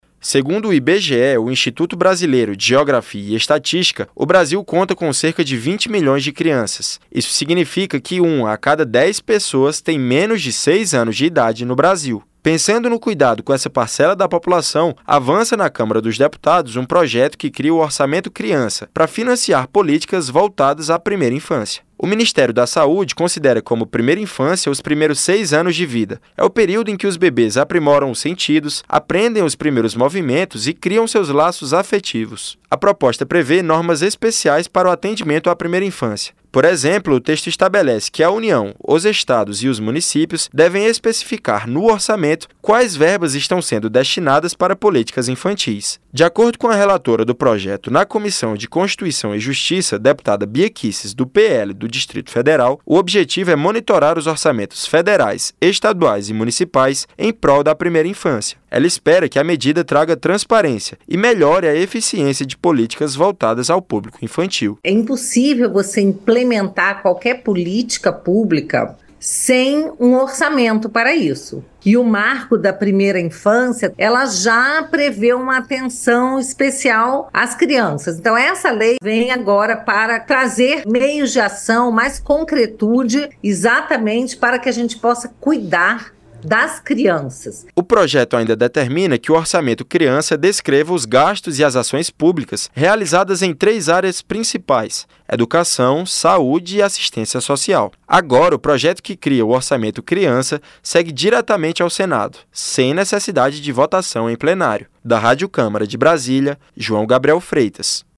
Comissão aprova criação de orçamento especial para crianças - Radioagência